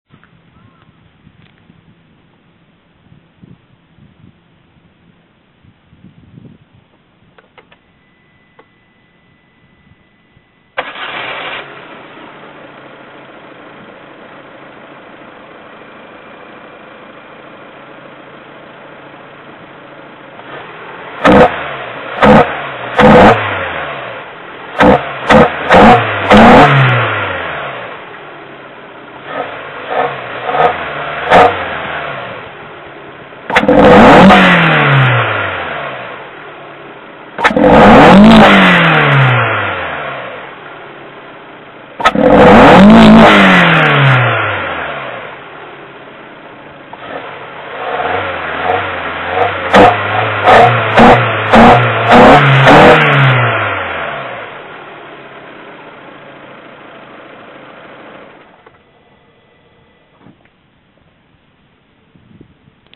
さらにアクセルをブリッピングし、 一気に開けるとエアクリからの吸気音がものすごいです。 『シュゴッ！』って言います。 どの回転域からでも、アクセルを床まで一気に踏むと『シュゴシュゴ』言います。
PHSの録音機能でとったので、音量がまちまちですが、適当にあわせて聞いてください(^^;
エンジンルームでの吸気音